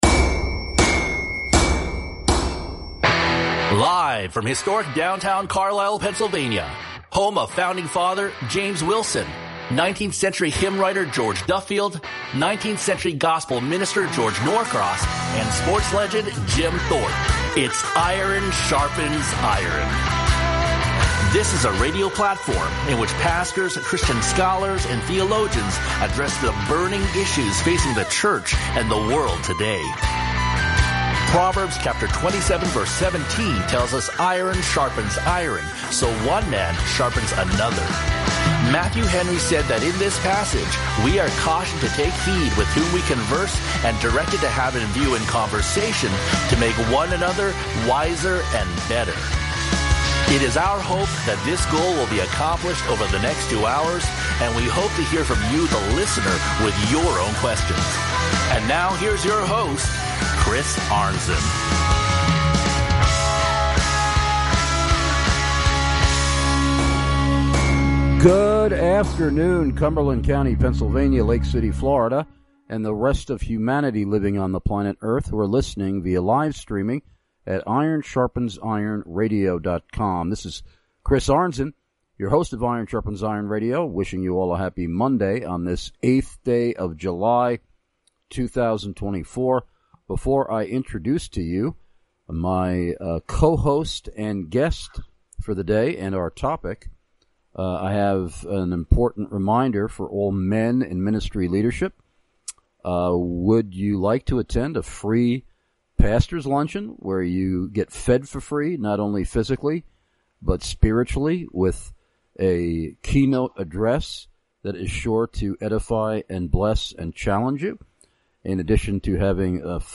a missionary to Indonesia